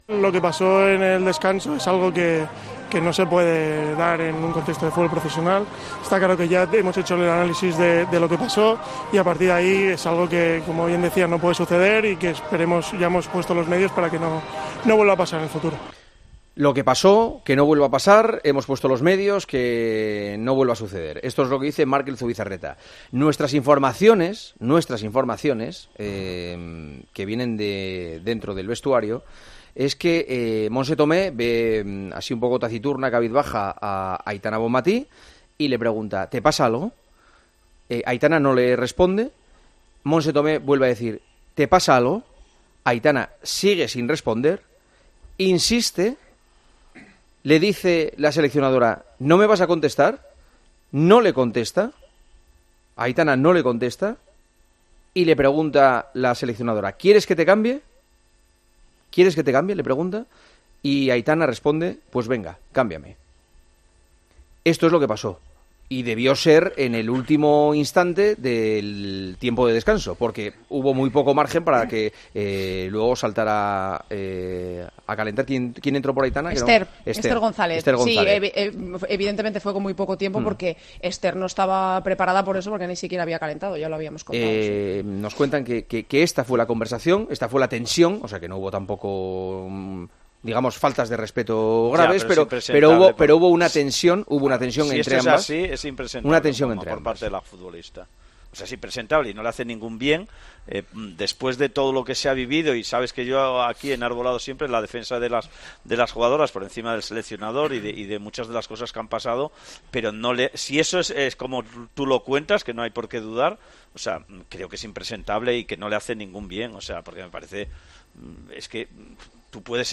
El director de El Partidazo de COPE ha contado el cruce de palabras que tuvieron la seleccionadora y la jugadora en el descenso del España-Italia.